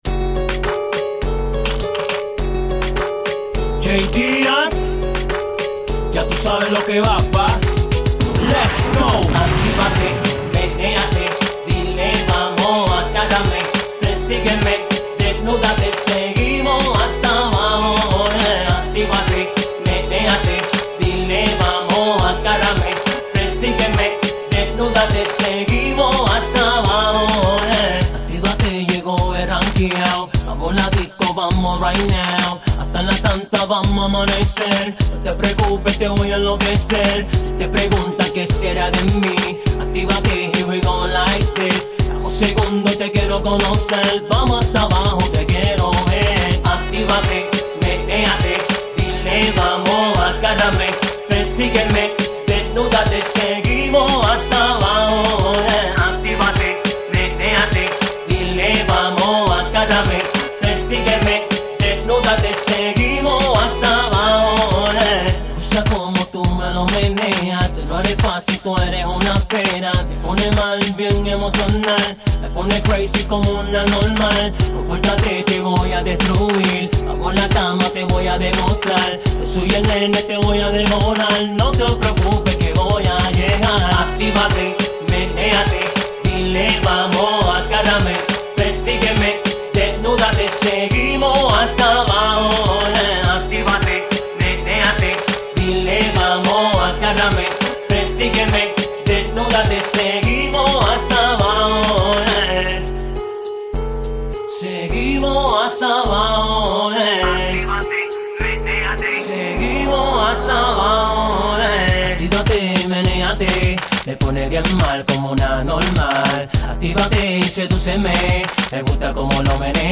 REGGEATON